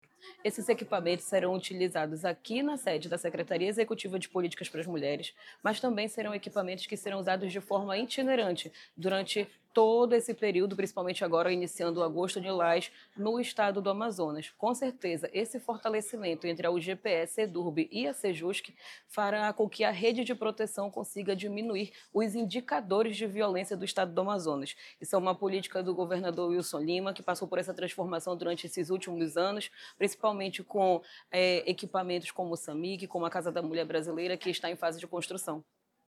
UGPE_SONORA-SECRETARIA-DA-SEJUSC-JUSSARA-PEDROSA.mp3